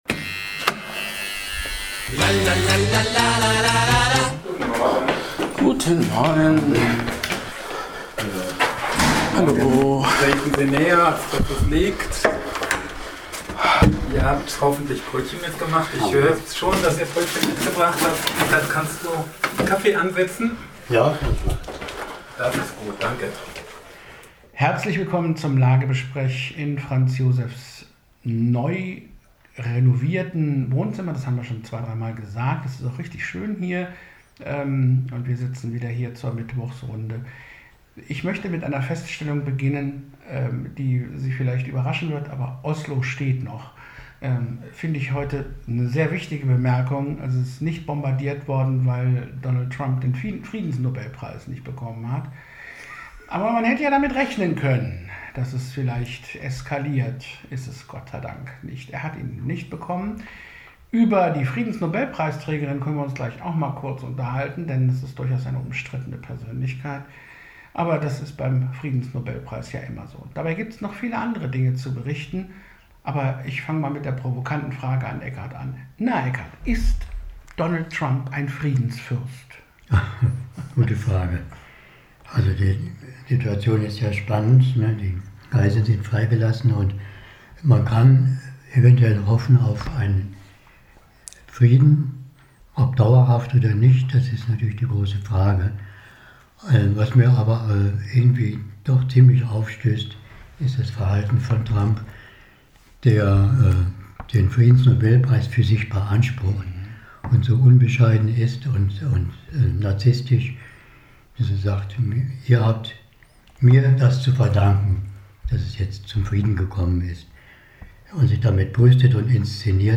Gespräche in der Mittwochsrunde